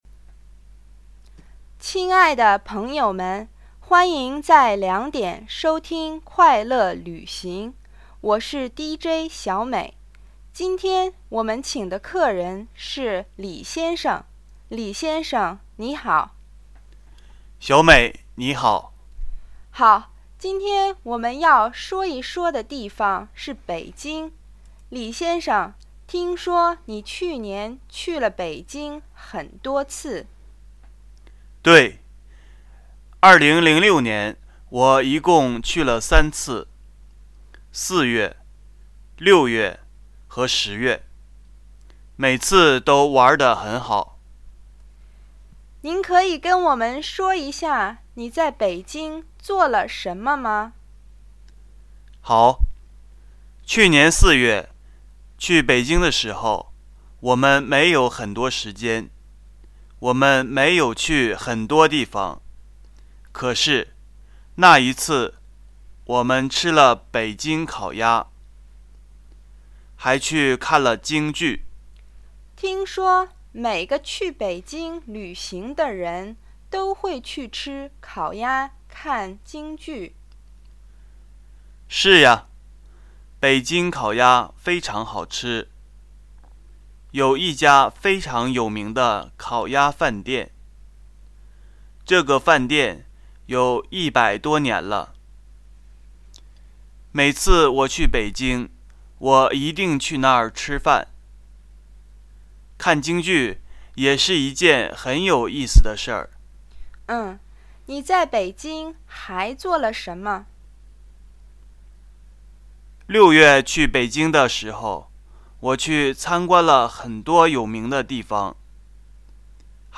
listening assignment radio.mp3